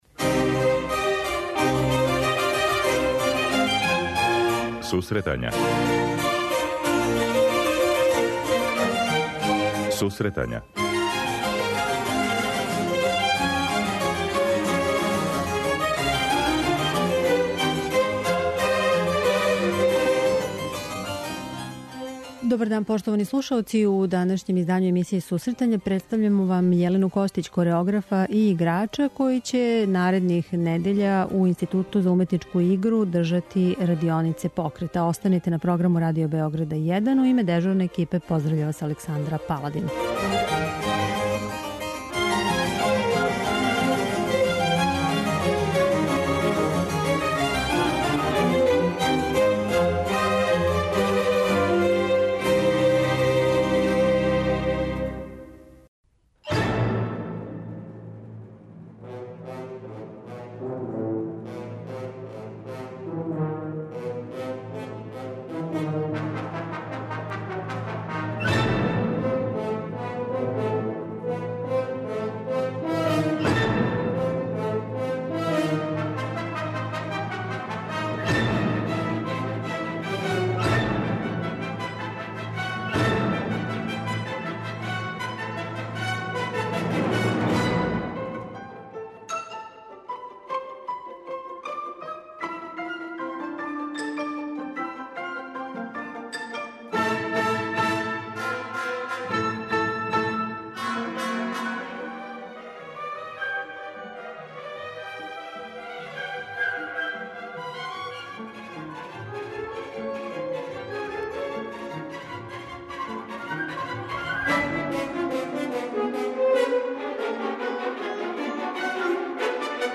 Са њом ћемо данас разговарати о идеји радионице, о савременим тенденцијама у балету и о њеној плесној трупи.